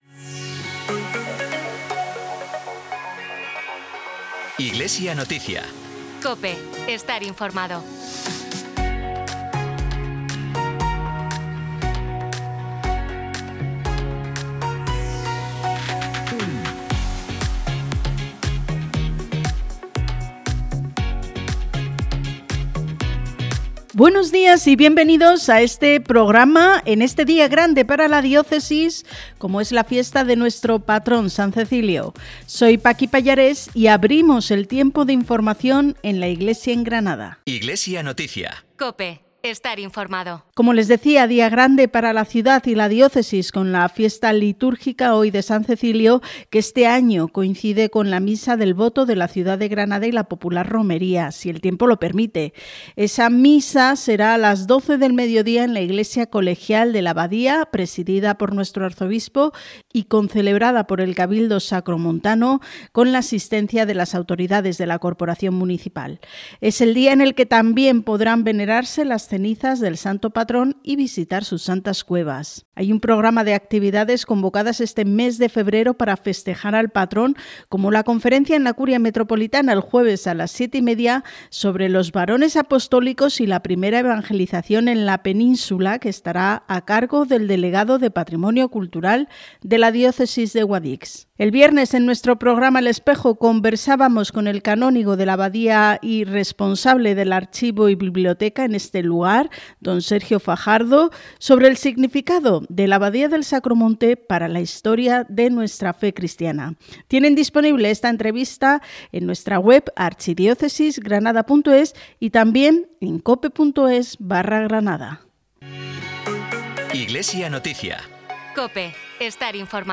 Programa emitido en COPE Granada y COPE Motril, el 1 de febrero de 2026.